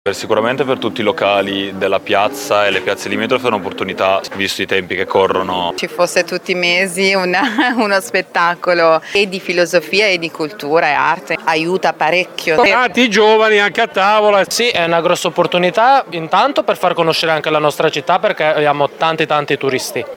Molto soddisfatti, anche quest’anno, per il gran pienone che il festival porta in centro i titolari dei locali modenesi. Sentiamo le interviste